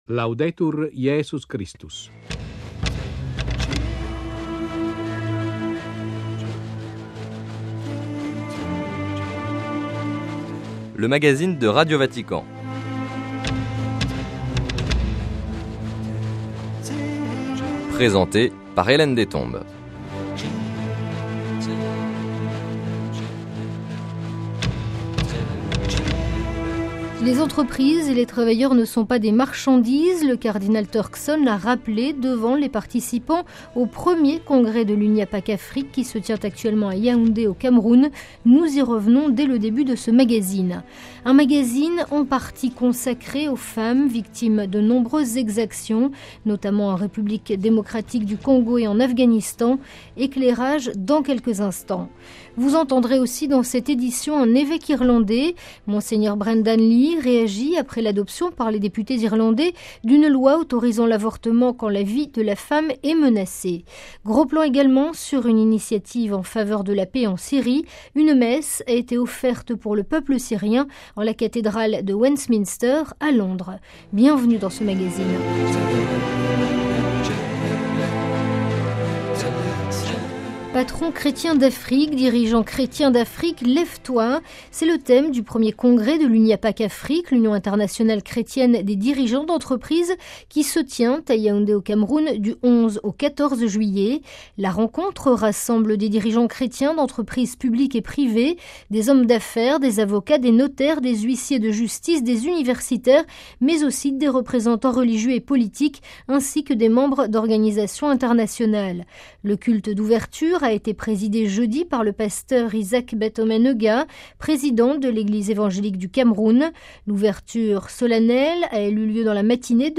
Sommaire - Intervention du Cardinal Peter Turkson, président du Conseil pontifical Justice et paix, lors du premier Congrès de l’UNIAPAC Afrique, à Yaoudé, au Cameroun. - Vingt ONG du Nord et Sud Kivu dénoncent les violences contre les femmes en République démocratique du Congo. Témoignage
Entretien